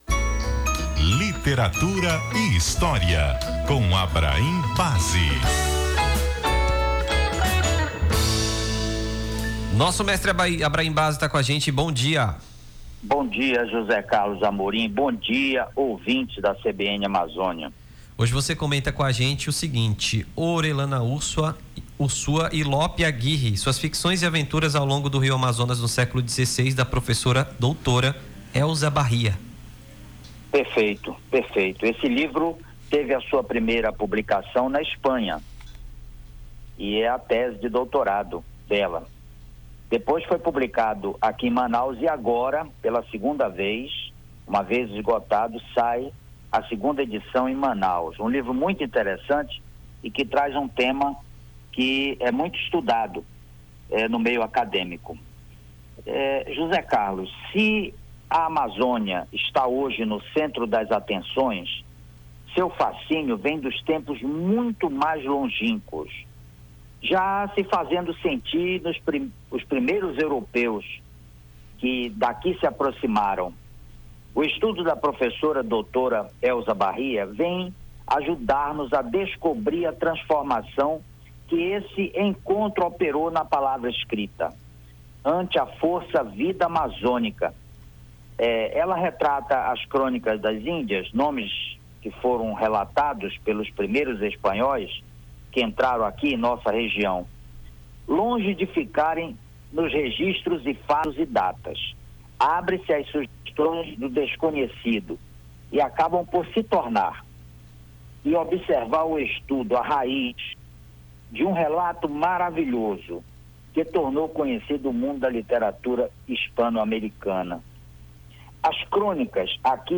Comentarista